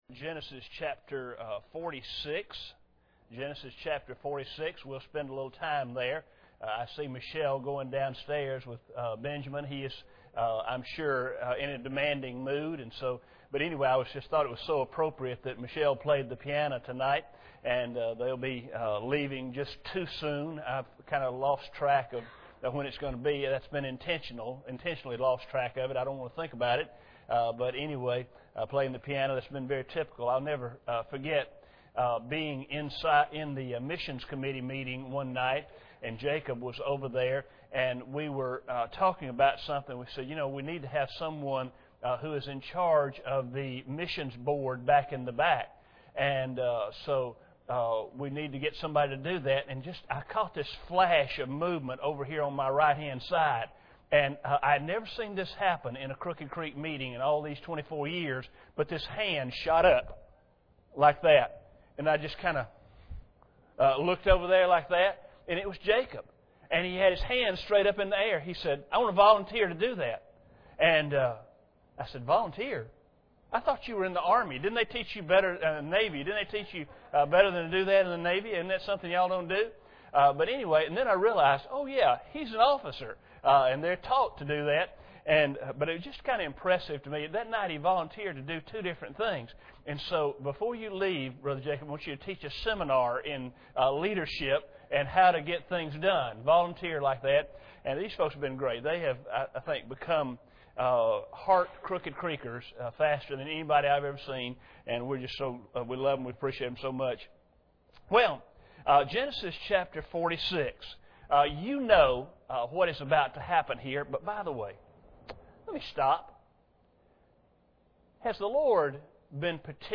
Passage: Genesis 46:1-34 Service Type: Sunday Evening